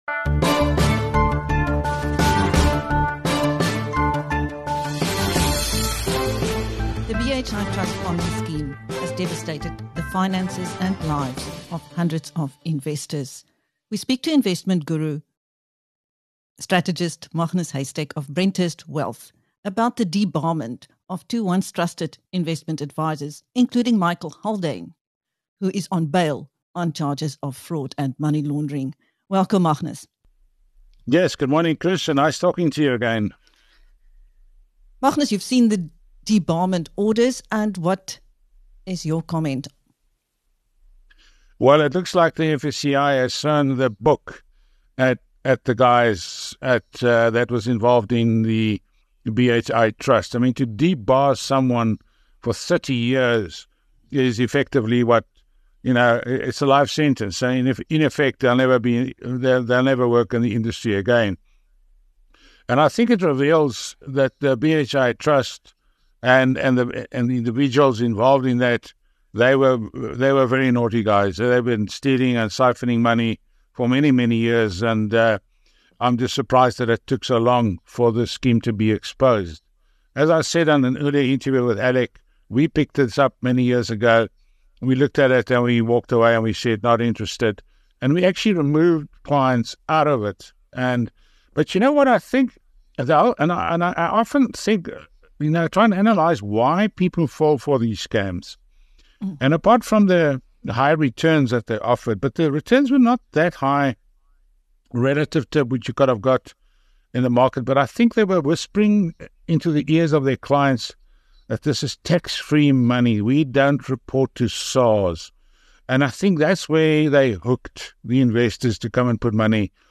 In the past year, South Africa’s Financial Sector Conduct Authority (FSCI) has debarred 156 people from the industry. In this interview with BizNews